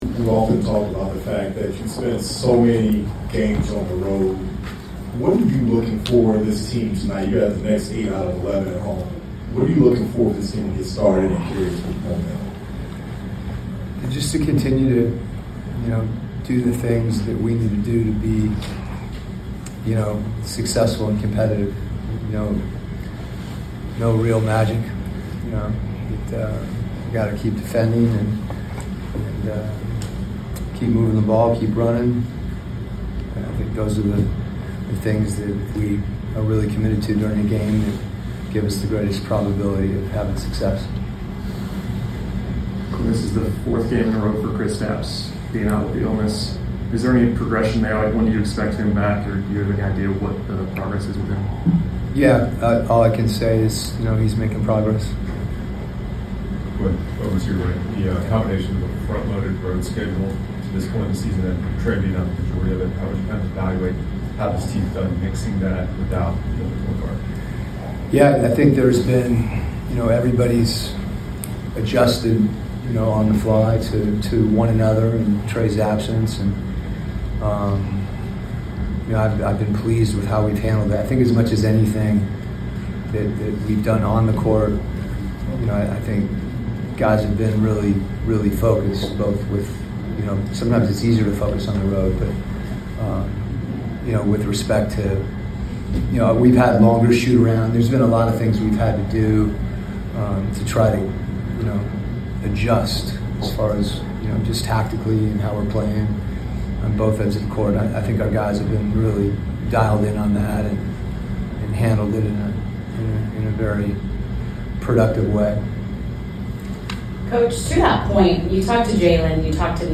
Atlanta Hawks Coach Quin Snyder Pregame Interview before taking on the Los Angeles Clippers at State Farm Arena.